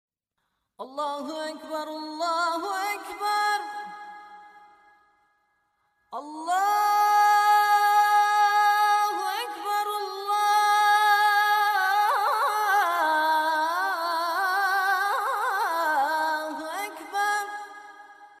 • Качество: 320, Stereo
молитва
Арабская молитва